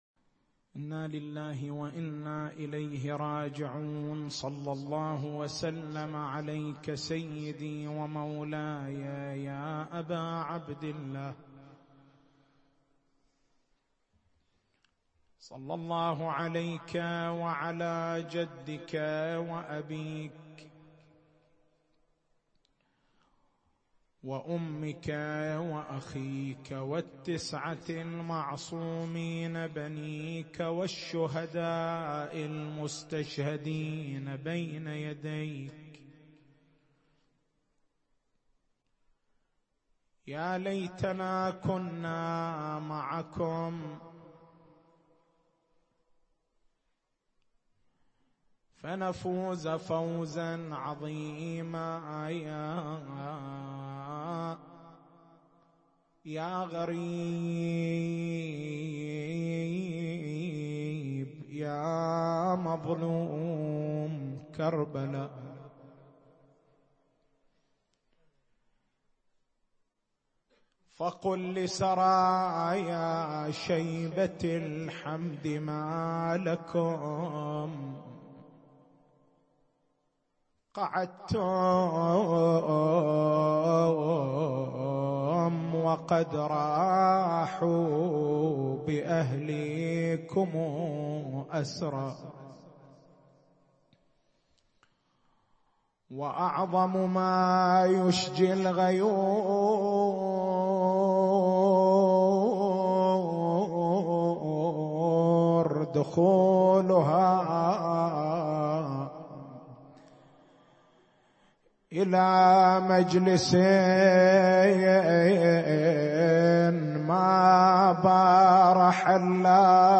تاريخ المحاضرة: 04/02/1439 نقاط البحث: هل الإطعام في مجالس سيّد الشهداء (ع) سنّة عامّة دلّ عليها إطلاق النصوص فحسب، أم هي سنّة خاصّة نصّت عليها الروايات بعينها؟